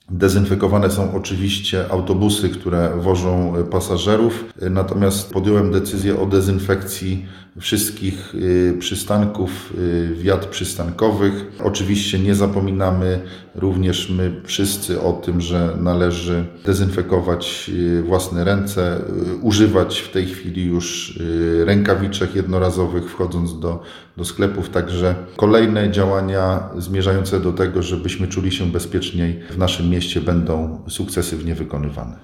Przedsiębiorstwo Gospodarki Komunalnej i Mieszkaniowej w Sandomierzu dezynfekuje wiaty przystankowe i autobusy. Mówi burmistrz Sandomierza Marcin Marzec: